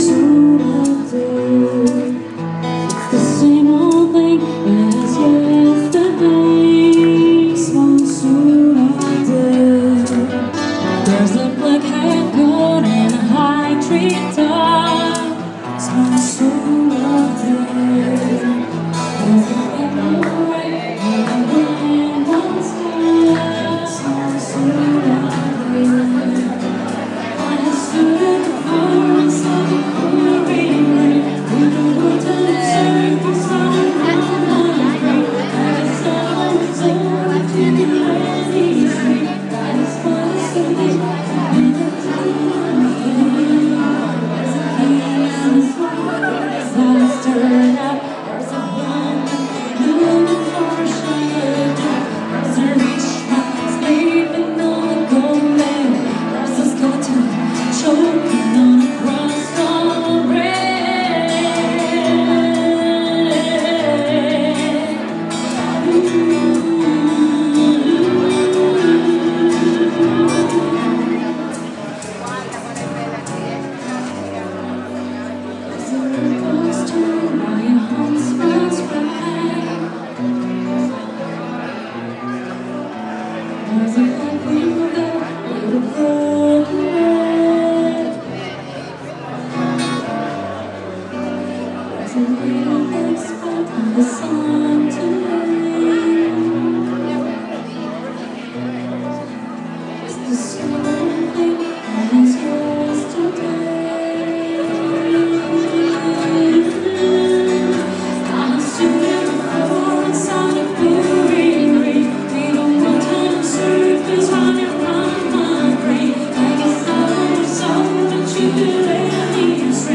Live in Barga - 5th night - Piazza Angelio